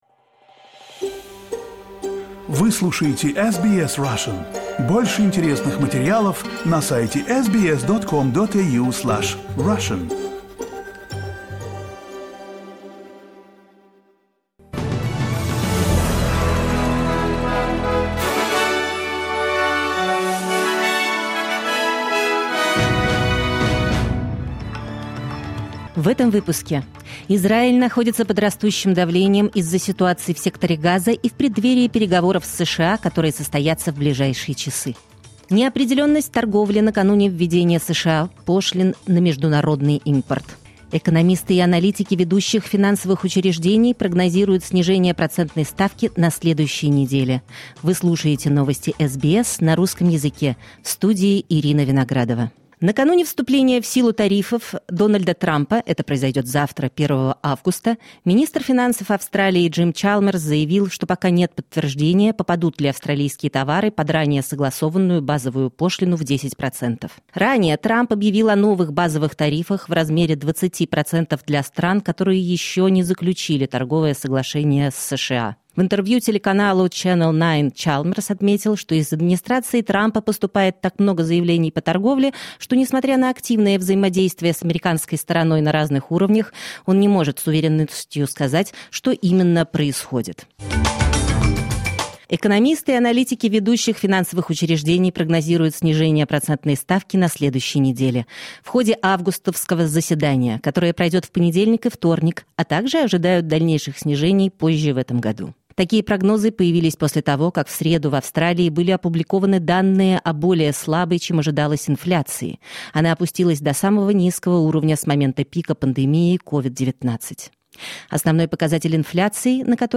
Новости SBS на русском языке — 31.07.2025